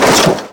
velox / Assets / sounds / collisions / car_light_2.wav
car_light_2.wav